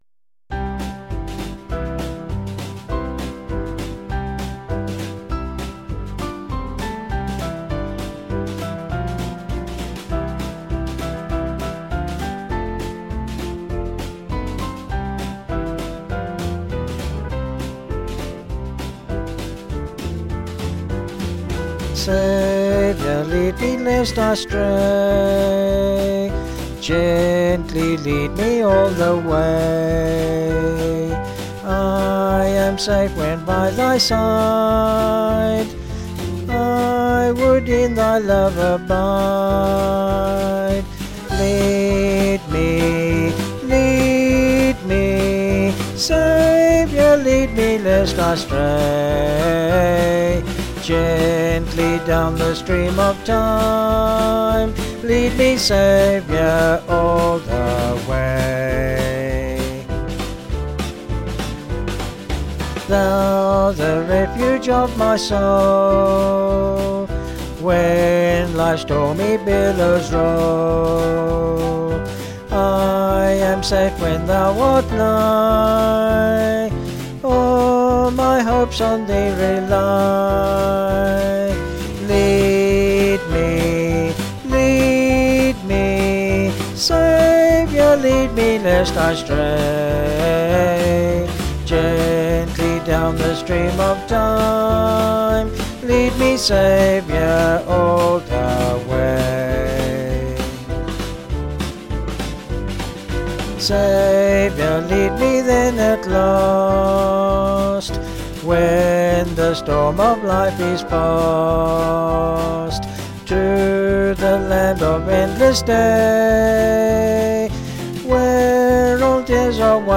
Vocals and Band   703kb Sung Lyrics